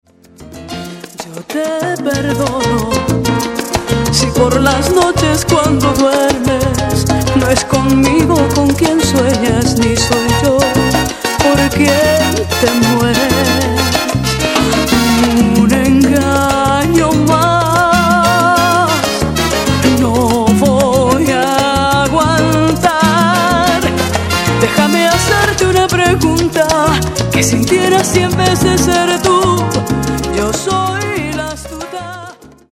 romantic and passionate songs